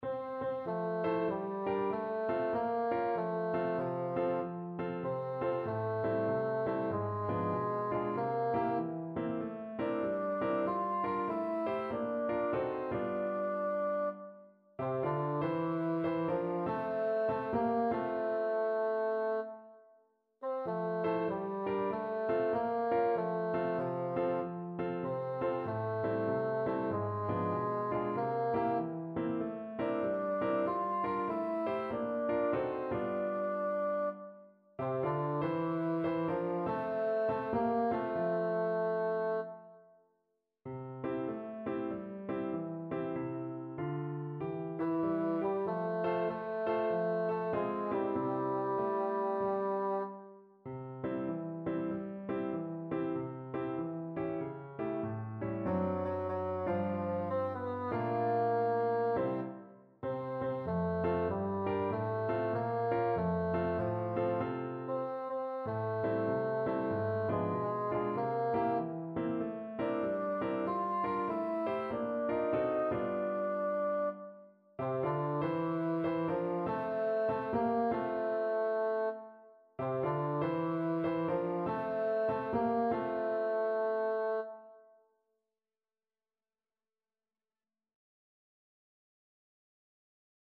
Julsånger
Let-it-snow-sab-ljudfil-tenor.mp3